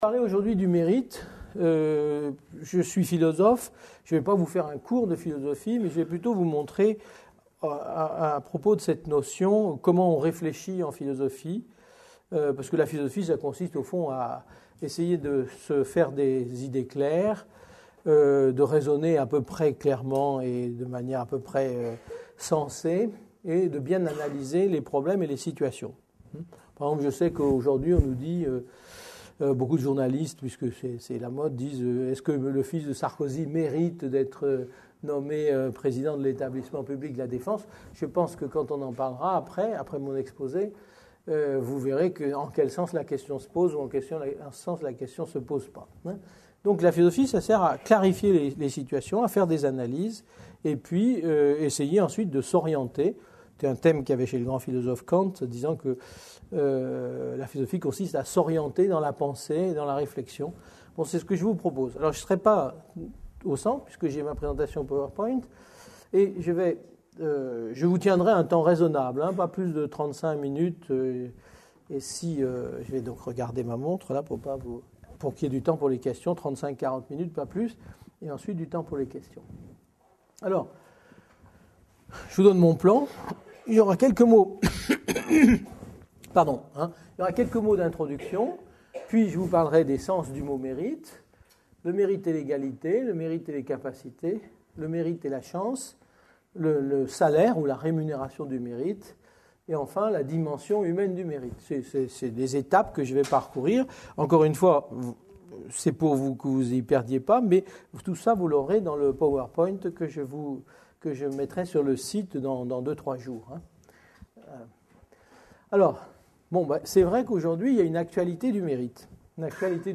Une conférence de l'UTLS au Lycée Qu'est ce que le mérite ? par Yves Michaud